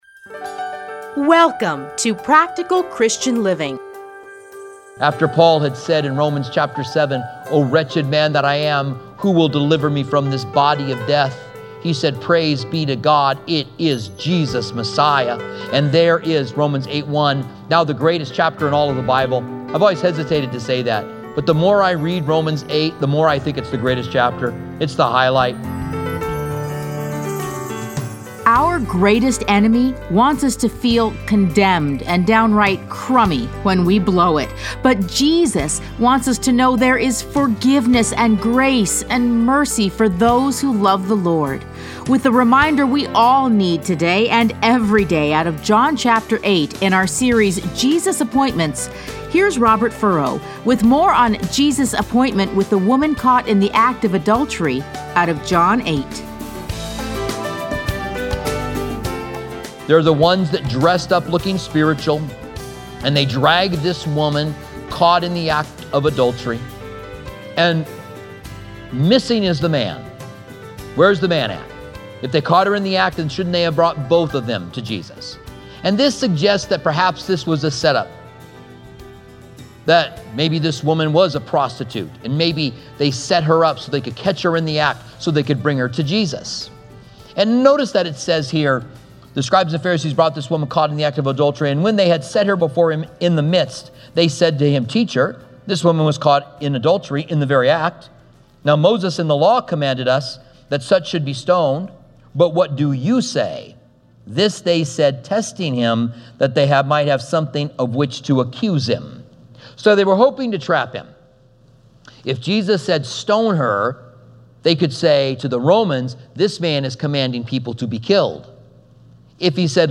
Listen here to a teaching from John.